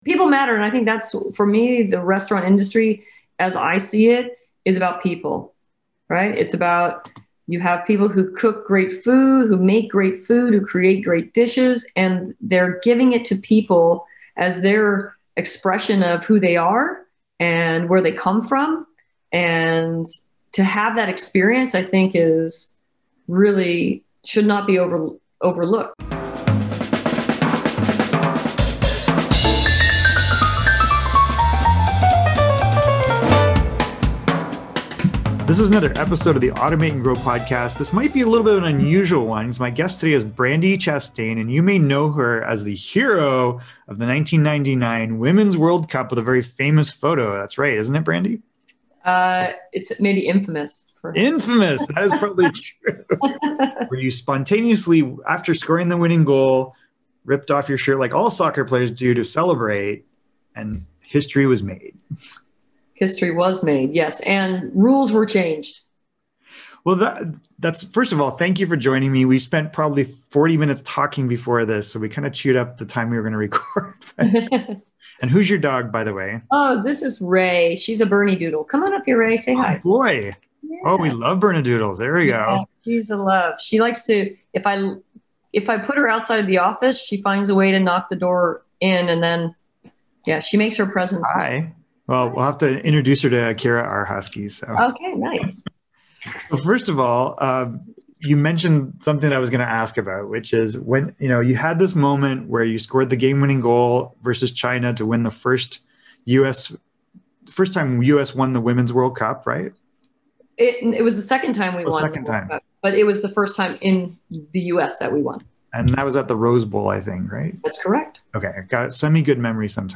My guest on Automate & Grow today is a legend in Women’s Soccer, Brandi Chastain.